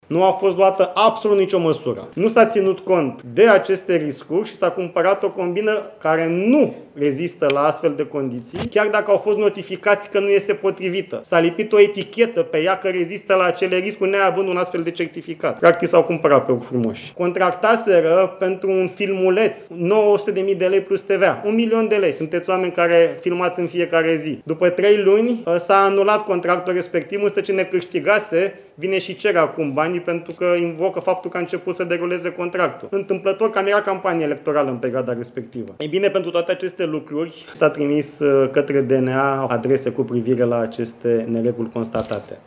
Ministrul Economiei, Radu Miruță: „Contractaseră pentru un filmuleț 900.000 de lei plus TVA, un milion de lei”